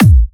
VFH2 140BPM Lectrotrance Kick.wav